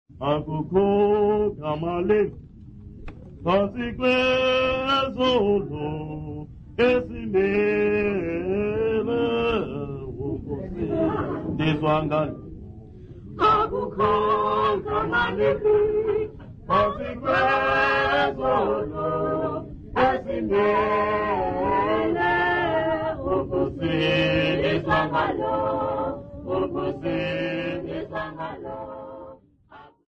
Zwelitsha church music workshop participants
Hymns, Xhosa South Africa
Folk music South Africa
field recordings
Church song sung at Xhosa composition workshop with clapping accompaniment.